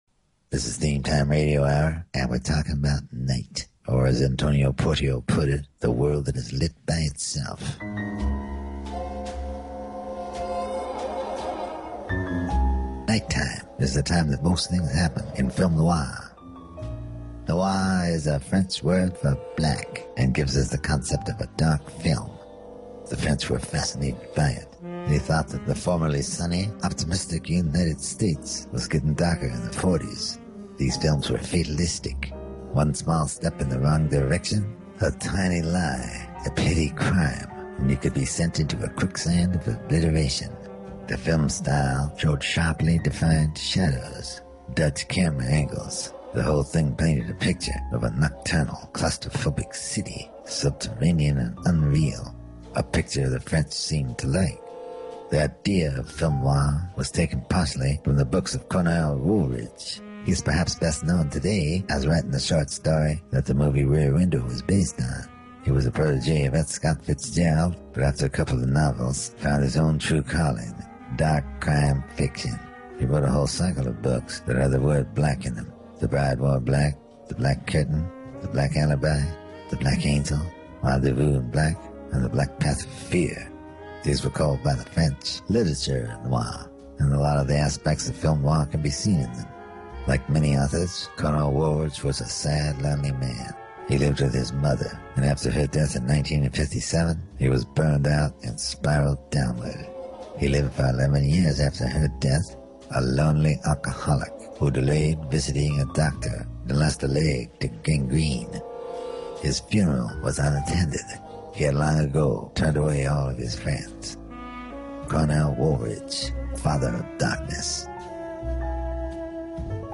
The one and only Bob Dylan speaks about noir and one of noir's darkest literary inspirations, Cornell Wolrich.